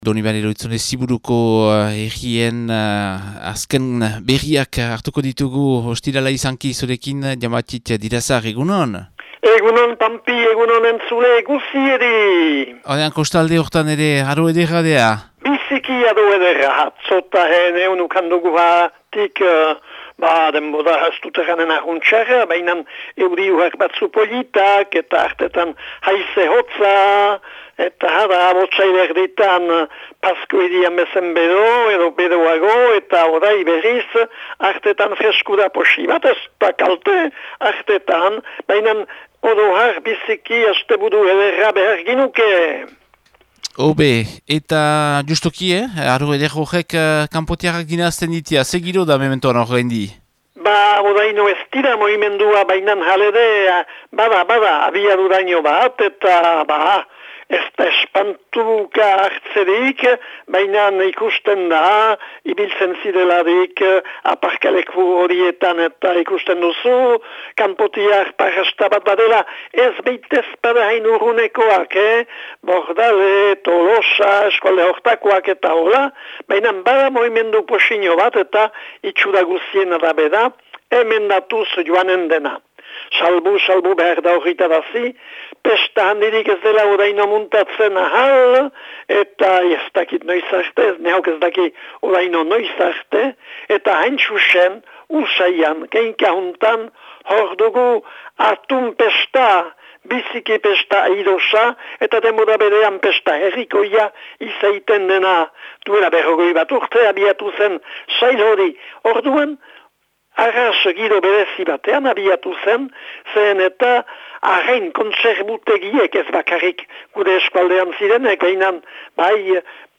laguntzailearen berriak.